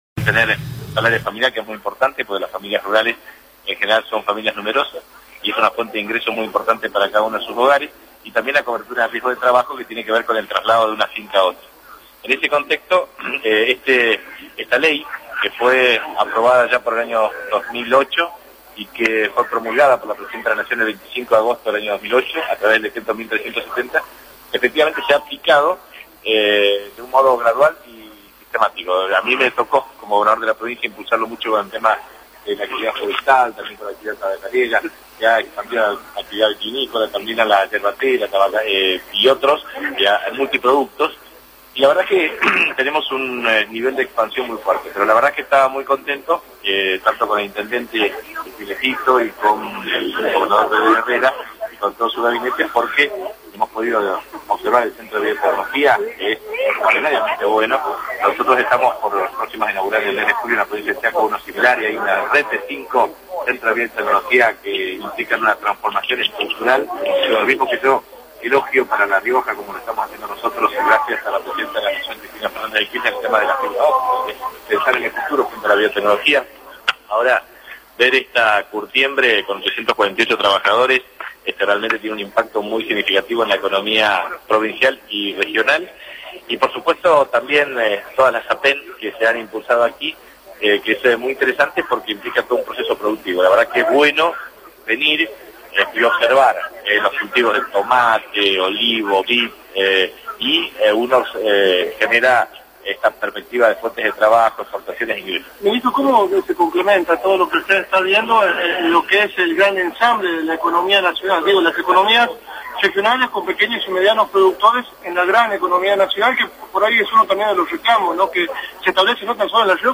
Capitanich habló con la prensa al recorrer la ex Curtiembre Yoma de Nonogasta